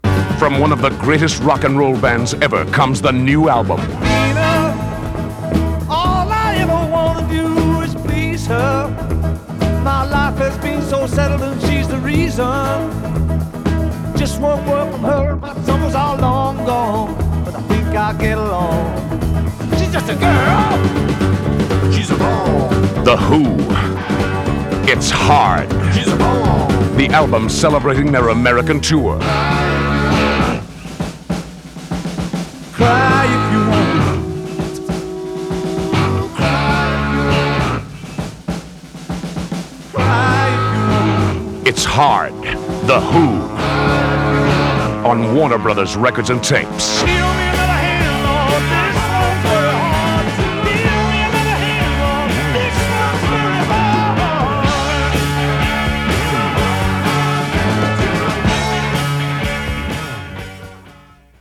1982 "It's Hard" Radio Commercial Reel-to-Reel Tape
The above are a small, reel-to-reel tapes - "spot" commercials advertising the same...